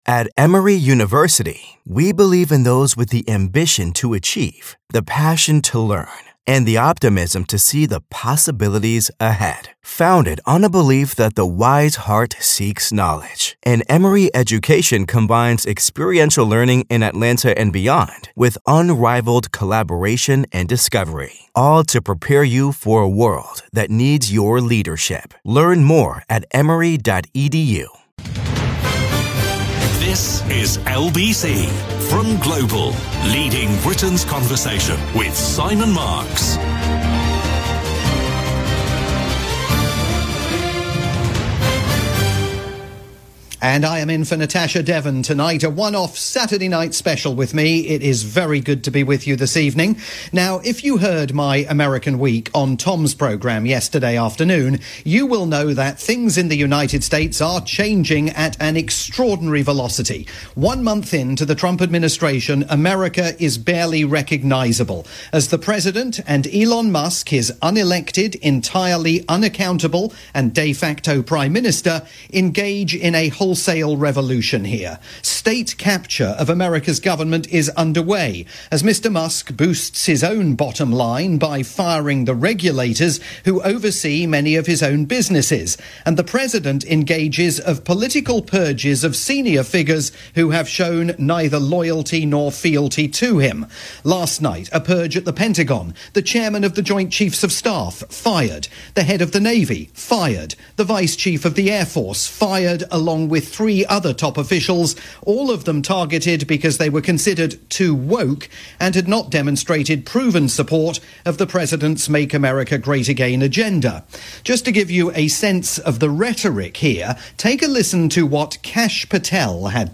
special Saturday night broadcast on LBC.